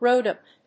アクセント・音節róad・bèd